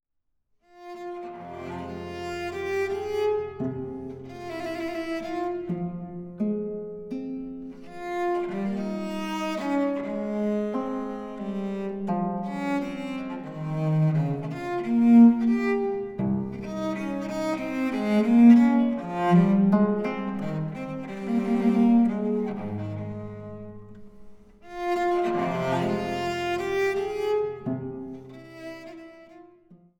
Gambenmusik - zahlreiche Ersteinspielungen
Diskant- und Bassgambe